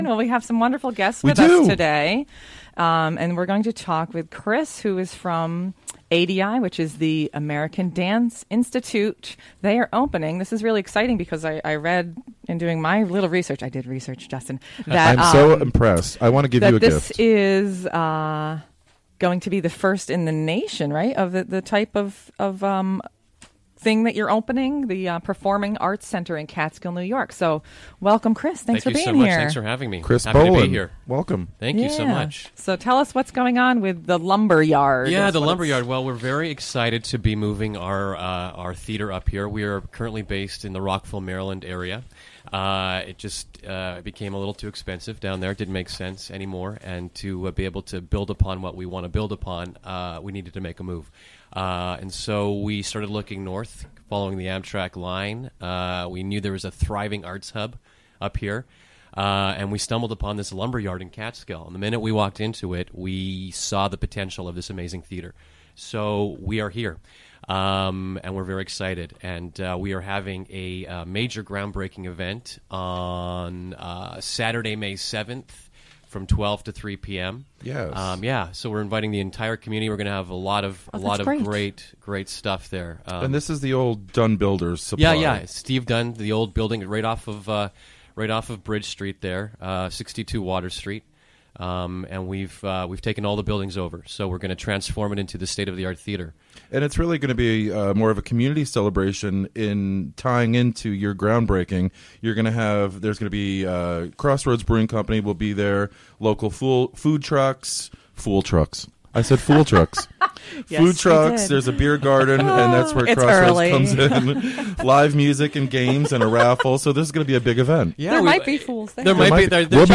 11am The show features interviews and discussion with p...